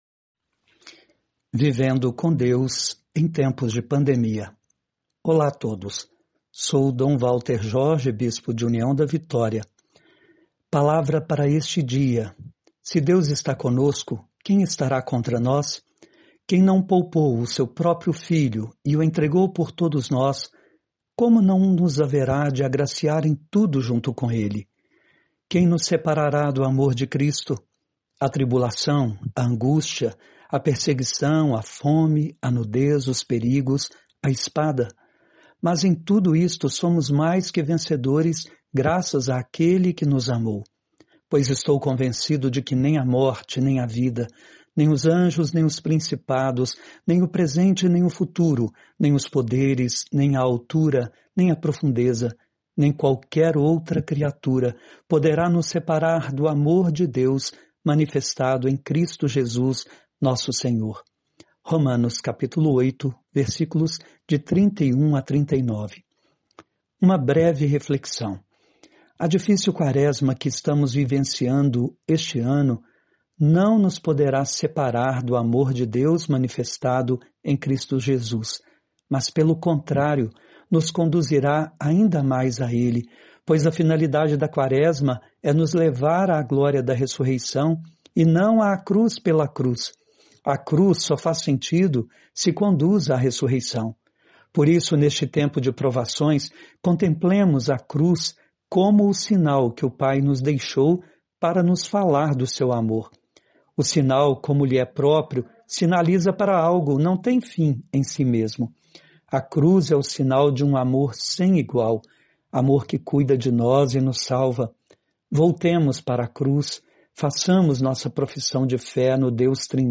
Reflexão de 27.03.2020 com Dom Walter Jorge